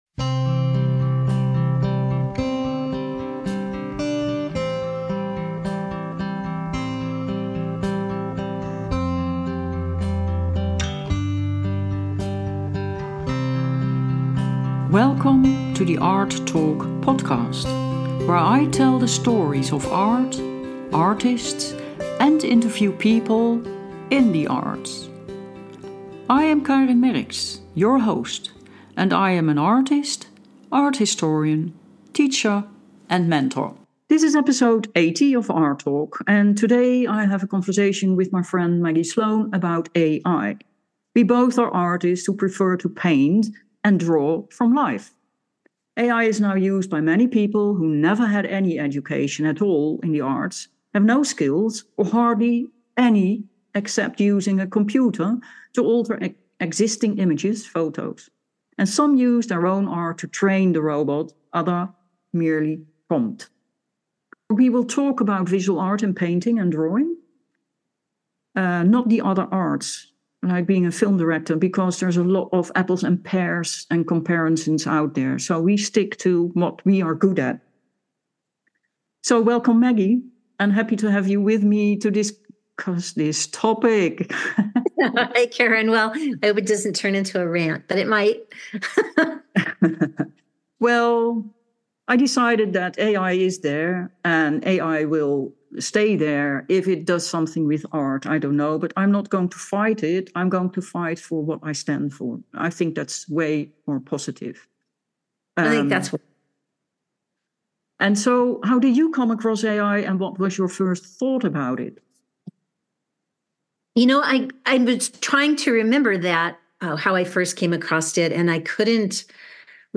A Conversation About AI -